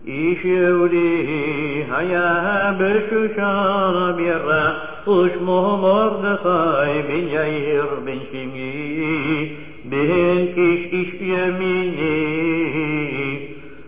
The following verses are sung by the kahal, and repeated by the Chazzan: